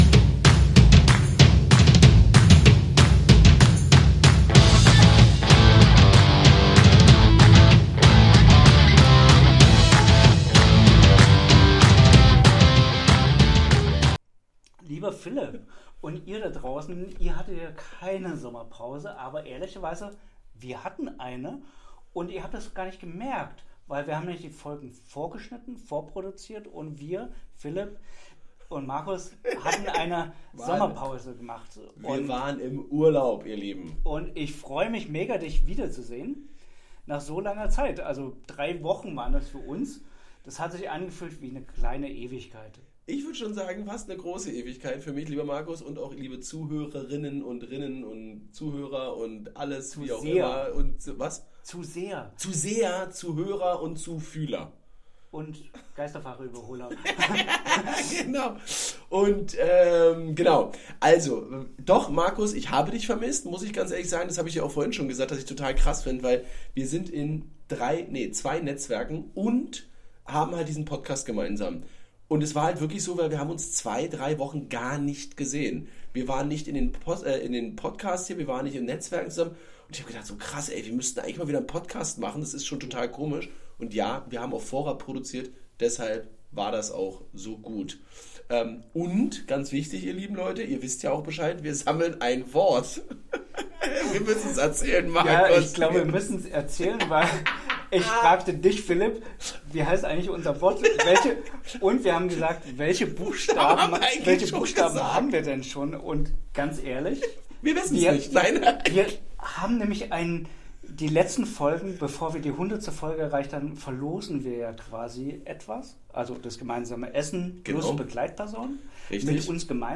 Themen dieser Episode: Warum Pausen und bewusstes Auftanken kein Luxus, sondern eine unternehmerische Notwendigkeit sind Was uns in den letzten Wochen bewegt hat und welche Learnings wir daraus ziehen Ein Blick nach vorn: Wie geht’s weiter im Business-Alltag und mit diesem Podcast? Und ja – ein bisschen Smalltalk unter Unternehmern, den ihr so vielleicht noch nicht gehört habt Fun Fact: Ihr habt unsere Pause gar nicht richtig bemerkt, weil wir clever vorproduziert haben.